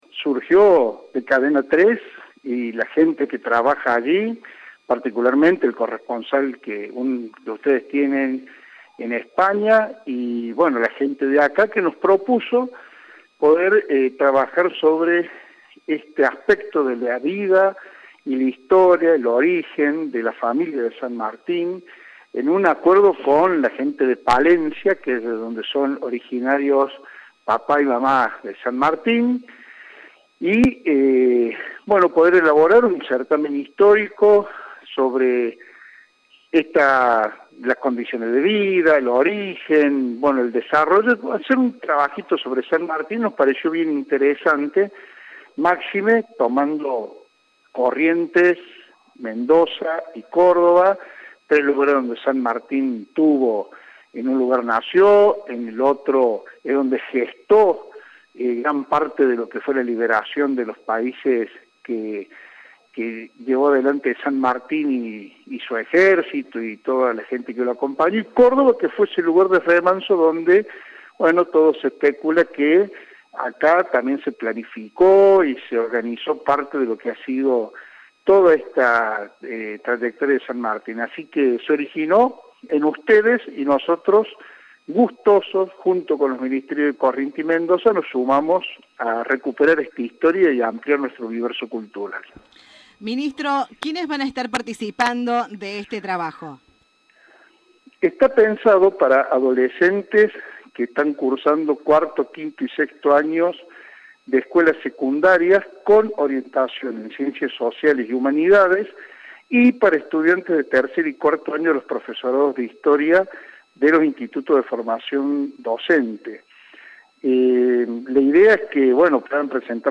Al respecto, el ministro de Educación de la provincia de Córdoba, Walter Grahovac, destacó la importancia del certamen, al que definió como “histórico”.
Informe